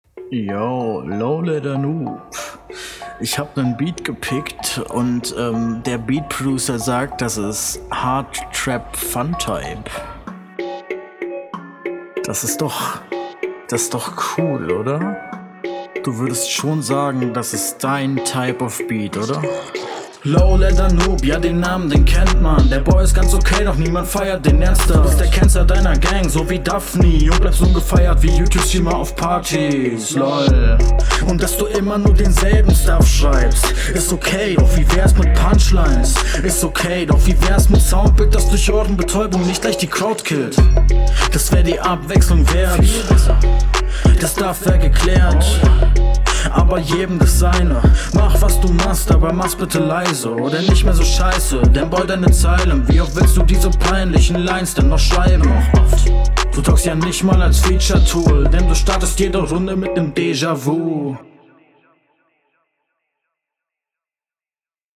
find den Stimmeinsatz am Anfang lame aber wird innerhalb des Tracks besser.